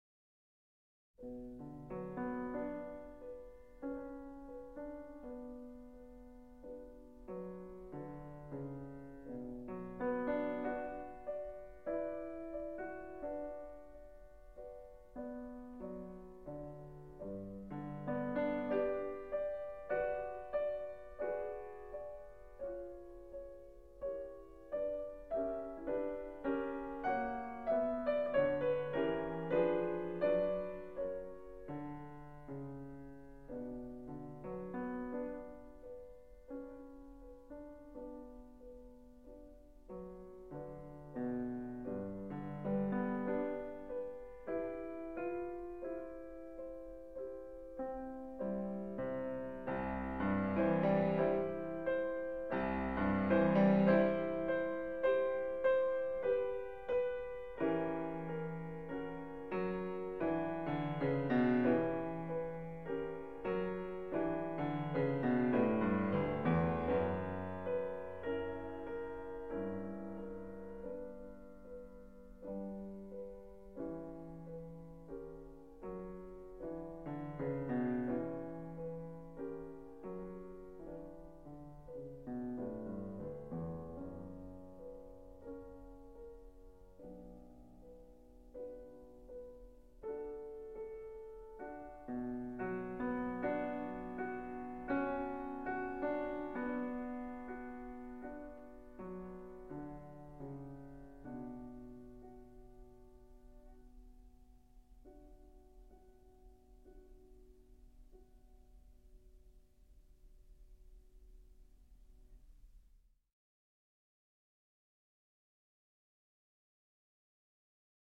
Prelude